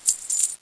jingbi.wav